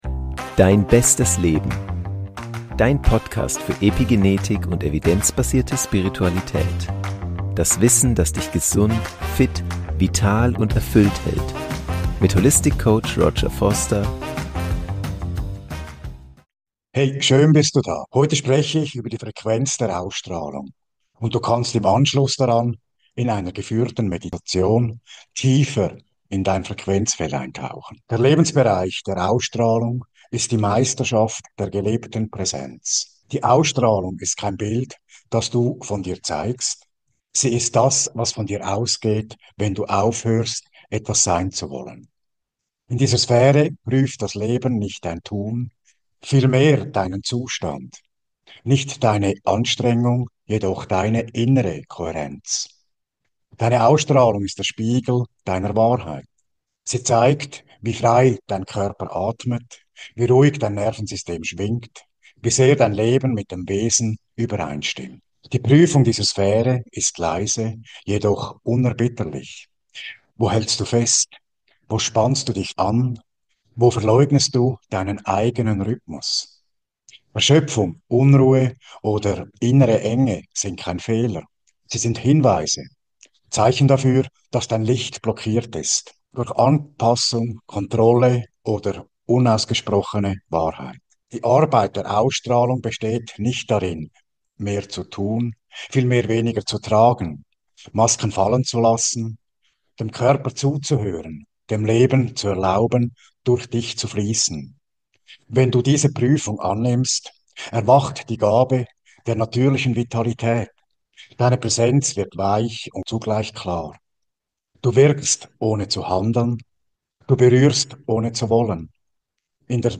Nach einem kurzen Intro tauchen wir gemeinsam in eine kontemplative Meditation ein - getragen von Musik und Stille - die Dich zurück in Dein eigenes Frequenzfeld führt.
Am besten mit Kopfhörern hören und Dir ein paar ungestörte Minuten schenken.